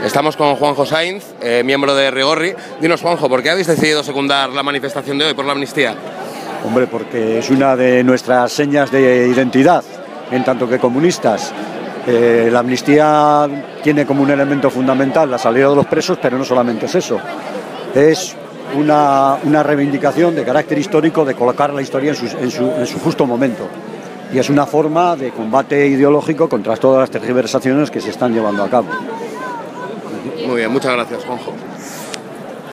LH entrevista